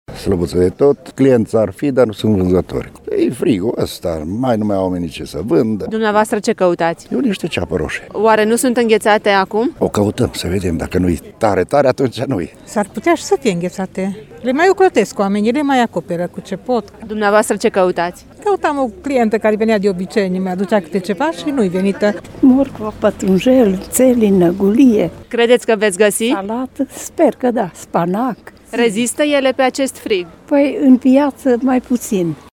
Doar câțiva cumpărători s-au sinchisit să iasă din casă pe frig, mânați de nevoia de aprovizionare și de obiceiul de a face piața dimineața: